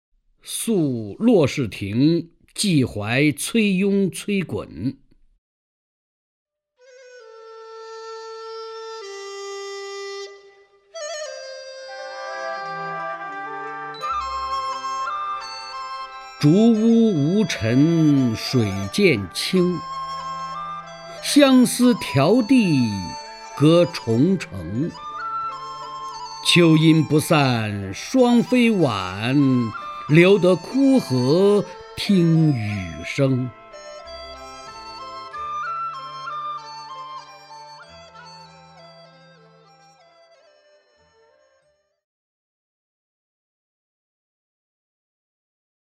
首页 视听 名家朗诵欣赏 曹灿
曹灿朗诵：《宿骆氏亭寄怀崔雍崔衮》(（唐）李商隐)